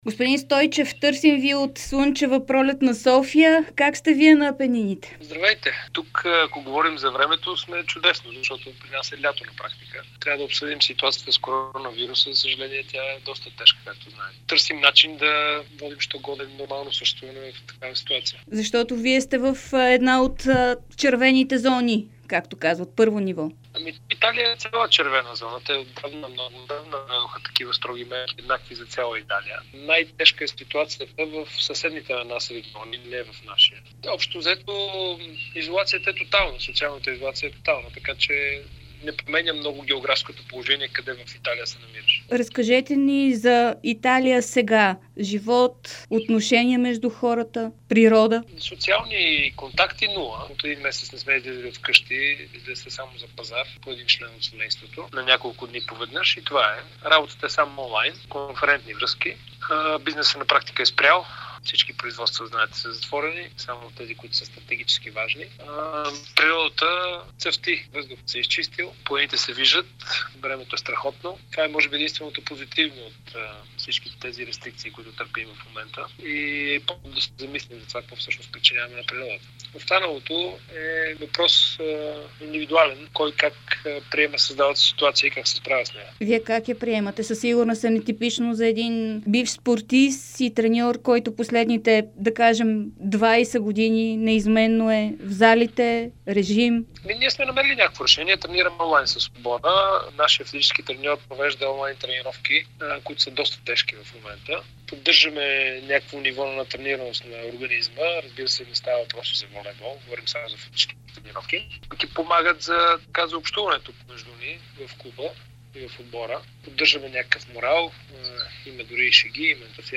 Старши треньорът на волейболния Калцедония Верона Радостин Стойчев даде специално интервю за Дарик и dsport, в което говори за ситуацията в Италия. Стойчев разкри и че не води преговори с националния отбор на Иран.